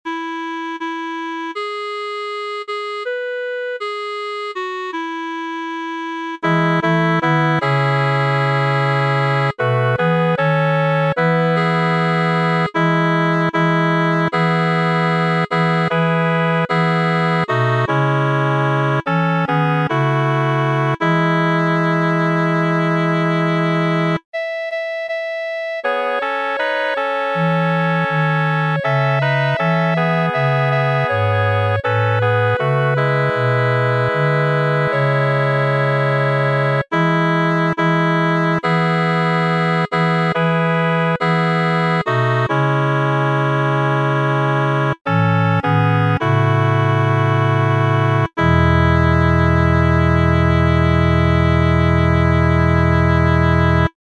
Sacré ; Spiritual Afro-Américain
affectueux ; enfantin
SATB (4 voix mixtes )
Tonalité : mi majeur